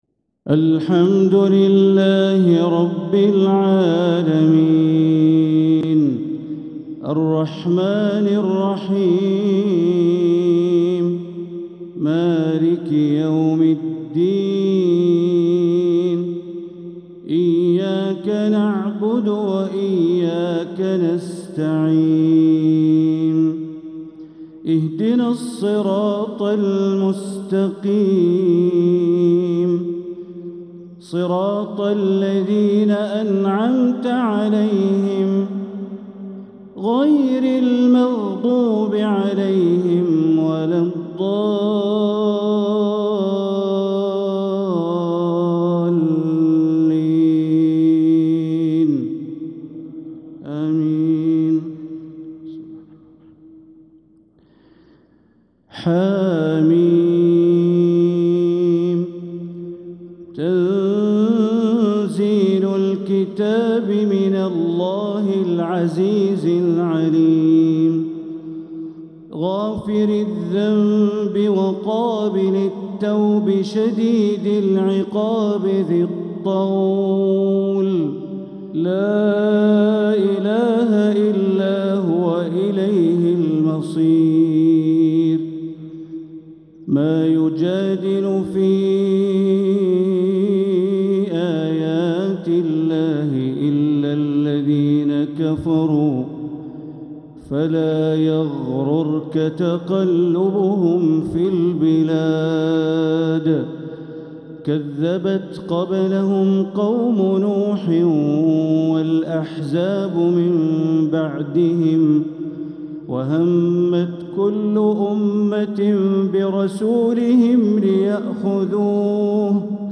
تلاوة خاشعة لفواتح سورة غافر | عشاء الأحد 8-5-1446هـ > 1446هـ > الفروض - تلاوات بندر بليلة